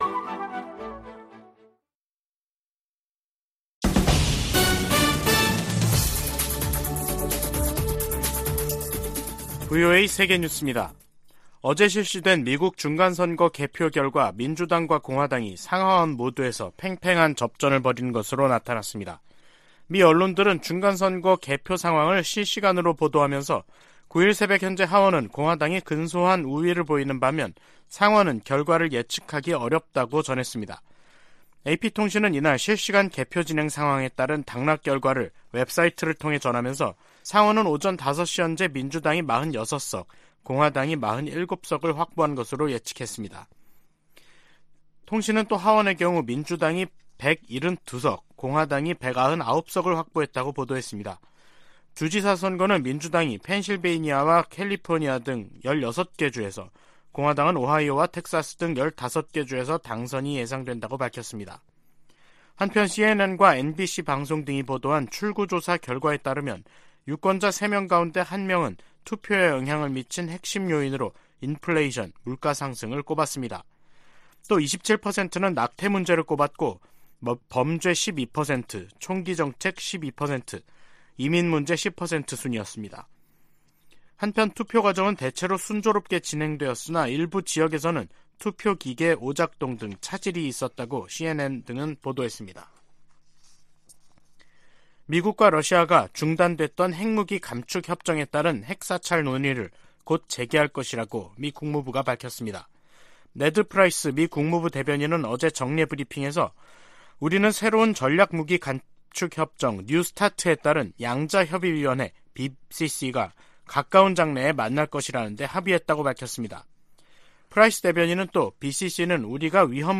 VOA 한국어 간판 뉴스 프로그램 '뉴스 투데이', 2022년 11월 9일 2부 방송입니다. 북한이 9일 오후 평안남도 숙천 일대에서 동해상으로 단거리 탄도미사일 1발을 또 발사했습니다. 8일 투표를 끝낸 미국 중간선거는 개표를 진행하고 있습니다. 공화당이 의회를 장악할 경우 미국의 한반도 정책에 어떤 변화가 생길지 주목되고 있습니다. 미 국방부는 북한이 러시아에 포탄을 제공하고 있다는 기존 입장을 재확인하며 상황을 계속 주시할 것이라고 밝혔습니다.